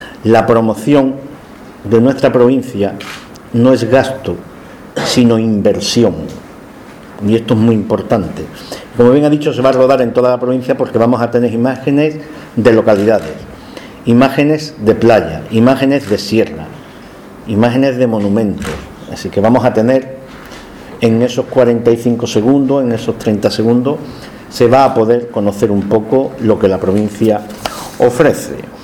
Loaiza habla sobre spot Vuelta a España
Jose-loaiza-sobre-spot-con-sara-baras.ogg